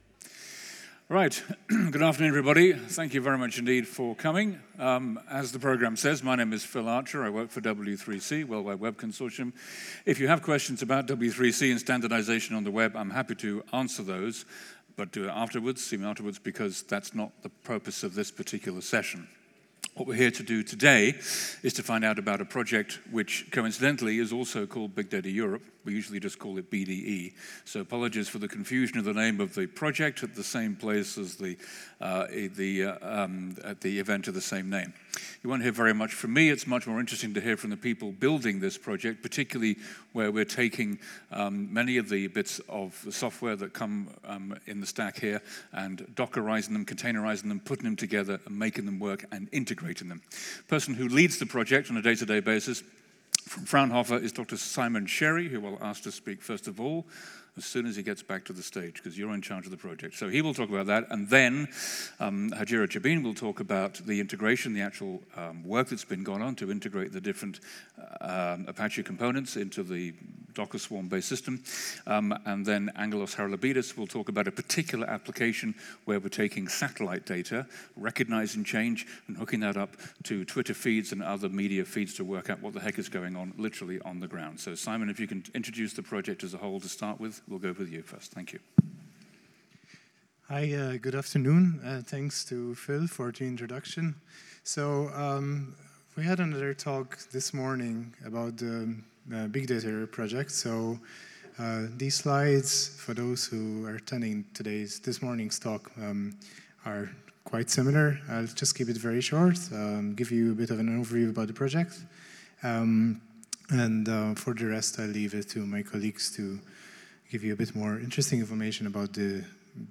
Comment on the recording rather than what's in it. Apache Big Data Seville 2016 – Integrators at Work!